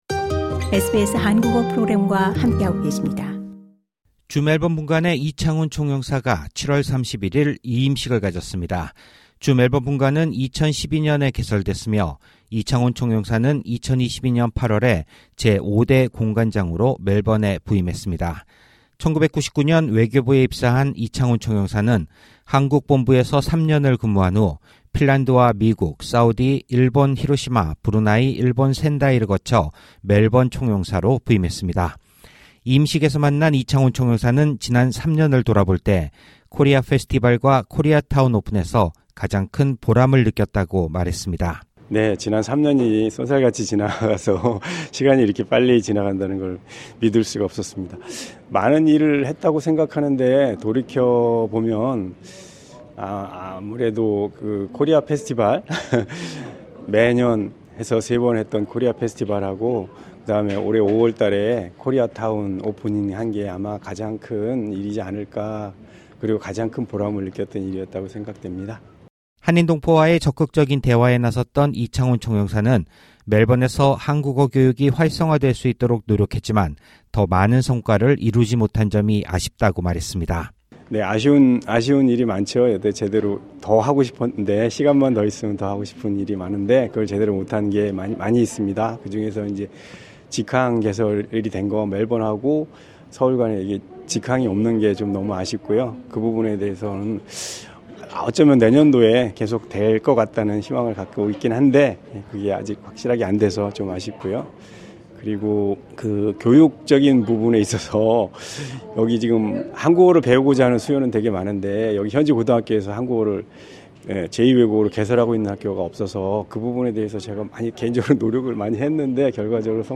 주멜번분관의 이창훈 총영사는 이임식에서 성공적인 코리아 페스티벌 개최와 코리아타운 오픈에서 가장 큰 보람을 느꼈다고 말했습니다.